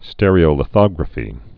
(stĕrē-ō-lĭ-thŏgrə-fē)